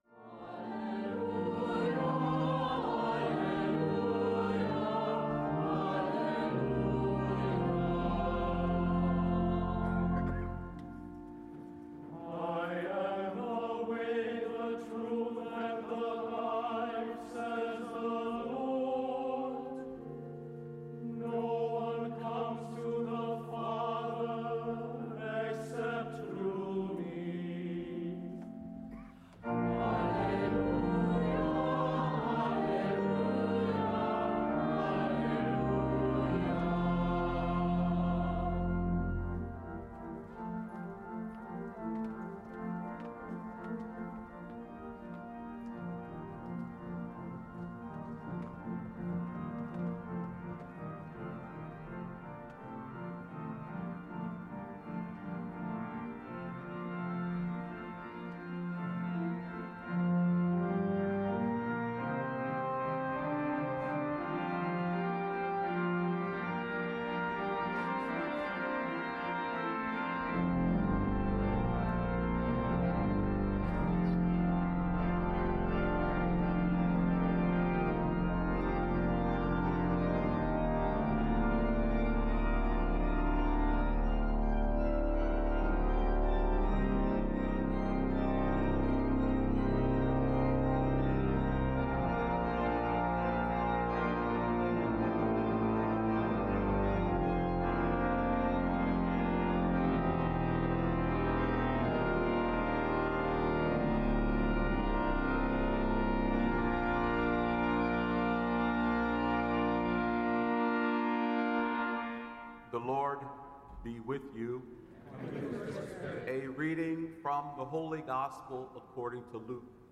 The Culmination of the 150th Jubilee Year Celebration of Saint Dominic' s Catholic Church San Francisco. Homily by The Very Reverend Gerard Timoner III, O.P., Master of the Dominican Order.